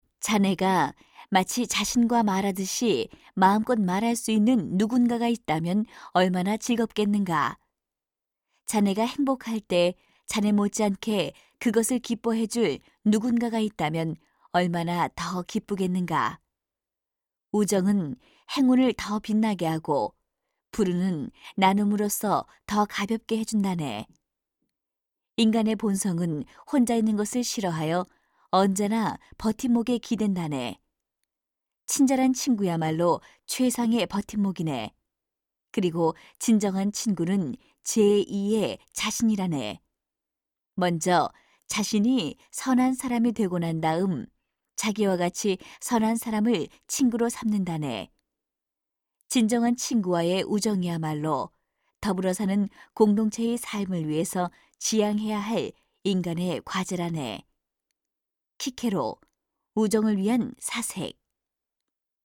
104쪽-내레이션.mp3